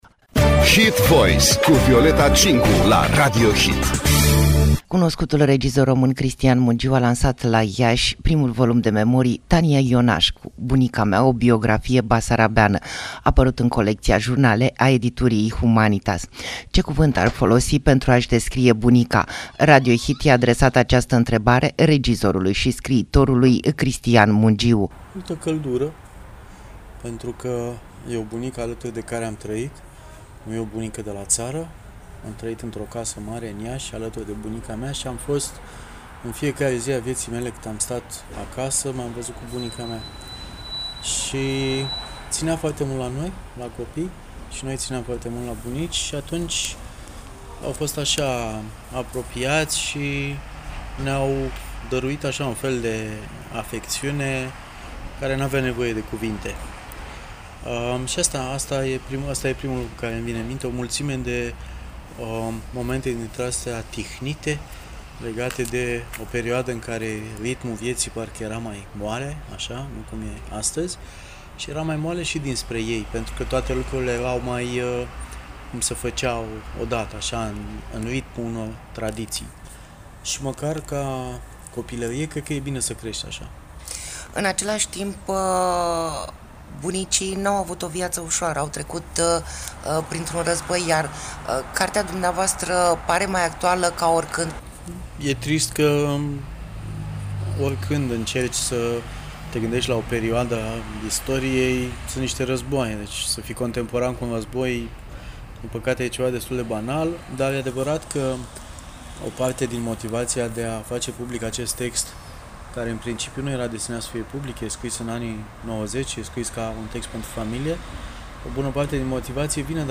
INTERVIU cu regizorul Cristian Mungiu: 12 ani de școală, bunica m-a așteptat cu o farfurie din care să degust până să fie masa gata - Radio Hit